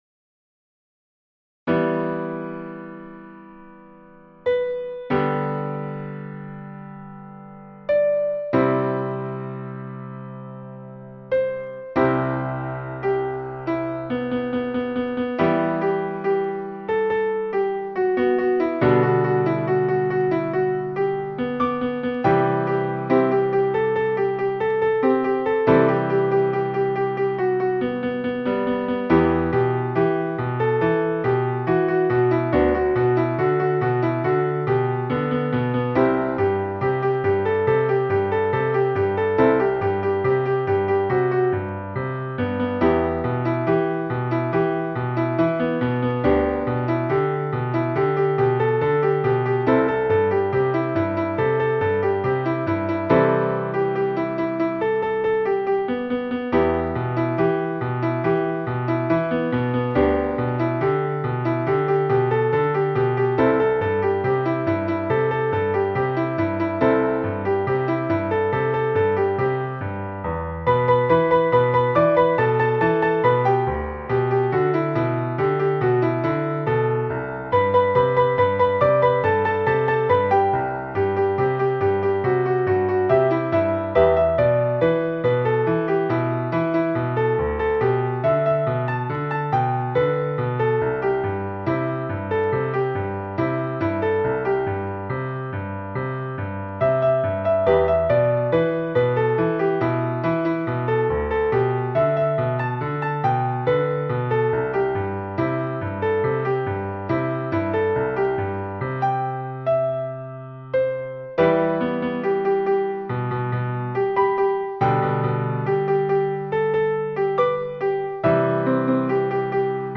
Ноты для фортепиано.
*.mp3 - МИДИ-файл для прослушивания нот.